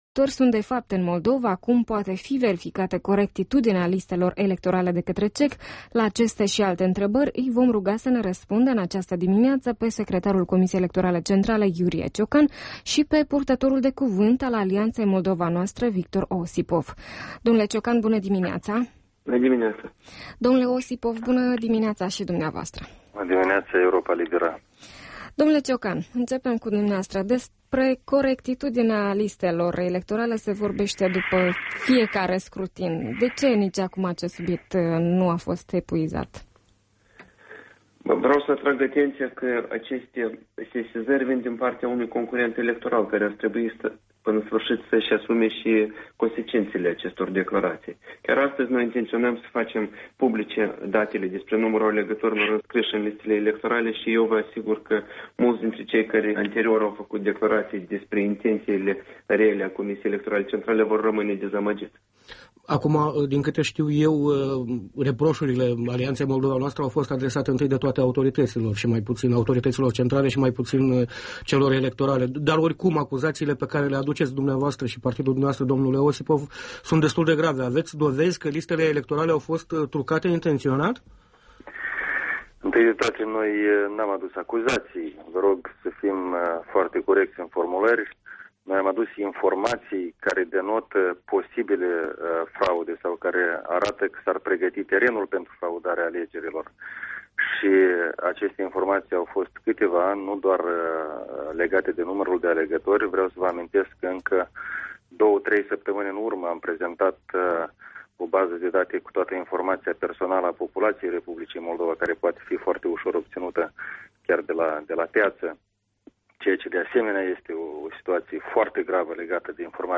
Interviu cu Iurie Ciocan și Victor Osipov